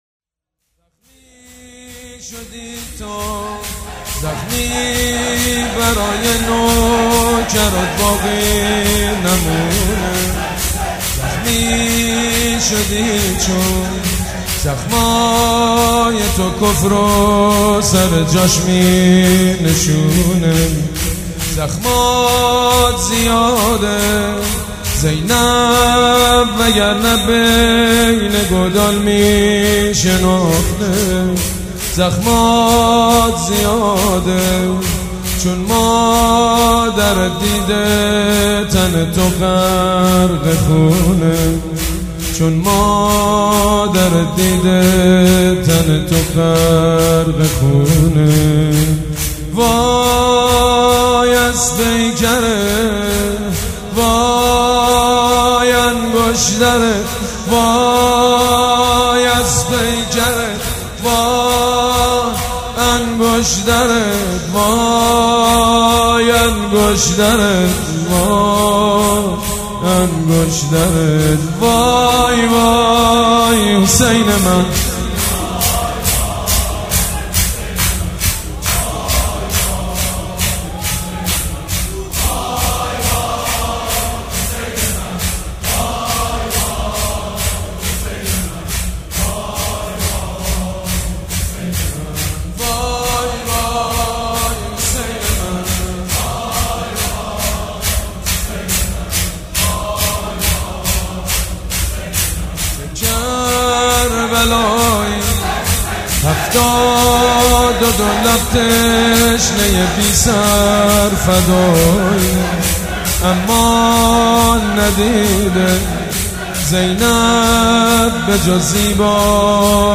«فاطمیه 1396» شور: وای از پیکرت، وای انگشترت